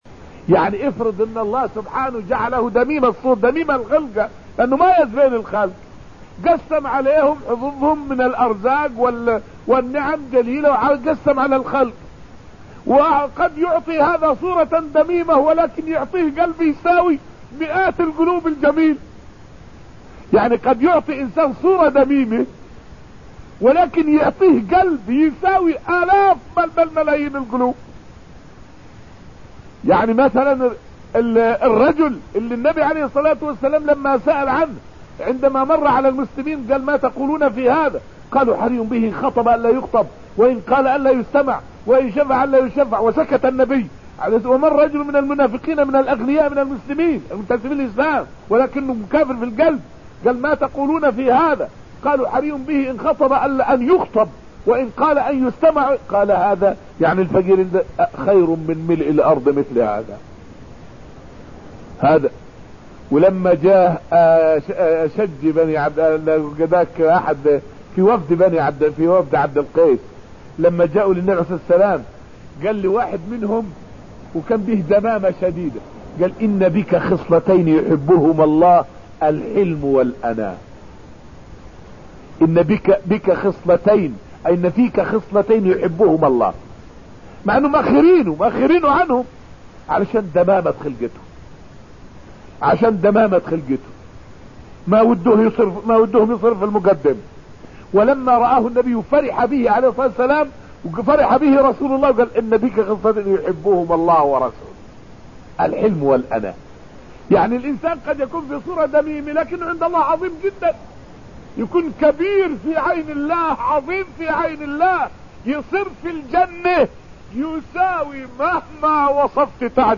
فائدة من الدرس الأول من دروس تفسير سورة الرحمن والتي ألقيت في المسجد النبوي الشريف حول فضل القرآن الكريم على غيره من الكتب السماوية وغير السماوية.